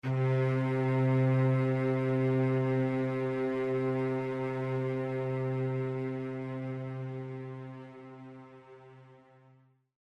Нота: До первой октавы (С4) – 261.63 Гц
Note2_C4.mp3